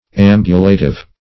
ambulative - definition of ambulative - synonyms, pronunciation, spelling from Free Dictionary Search Result for " ambulative" : The Collaborative International Dictionary of English v.0.48: Ambulative \Am"bu*la*tive\, a. Walking.